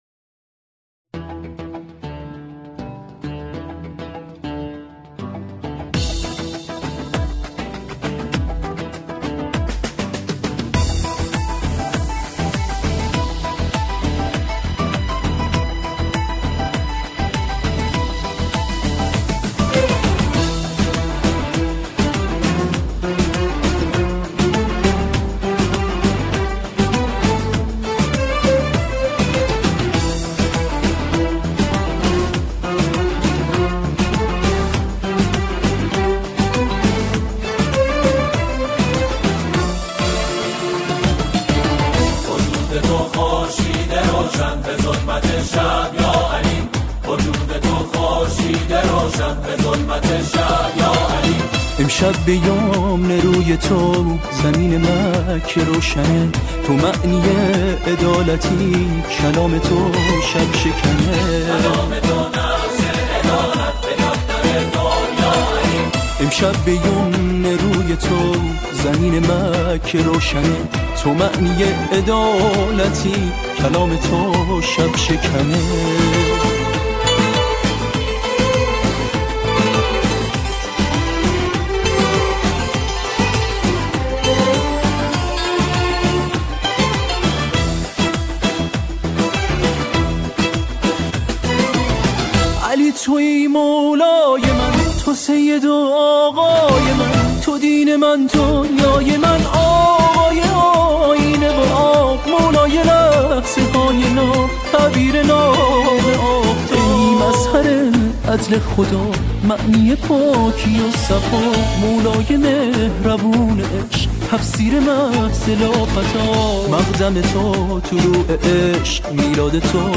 گروهی از جمعخوانان